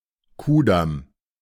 The Kurfürstendamm (German pronunciation: [ˌkuːɐ̯fʏʁstn̩ˈdam] ; colloquially Ku'damm, [ˈkuːdam]
De-Kudamm.ogg.mp3